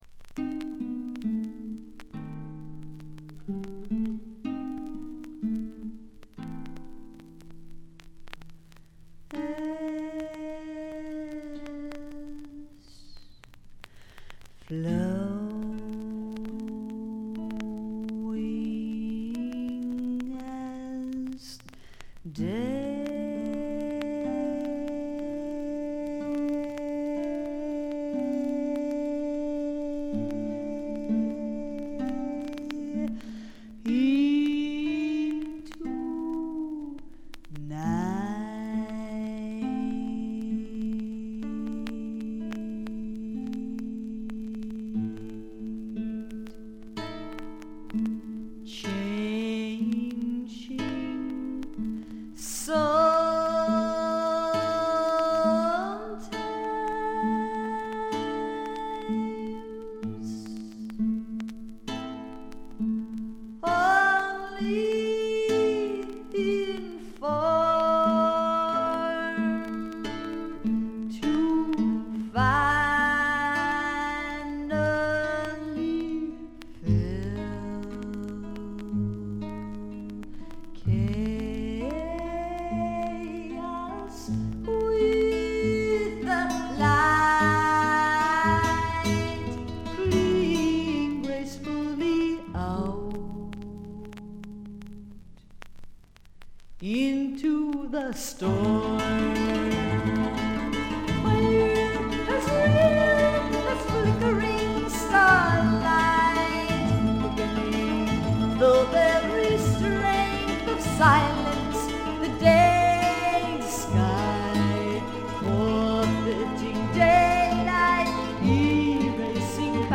常時大きめのバックグラウンドノイズが出ていますが、特に気になるようなノイズはありません。
スワンプナンバーでは強靭な喉を聴かせますが、アシッド路線では暗く妖艶で怪しいヴォーカルを響かせます。
試聴曲は現品からの取り込み音源です。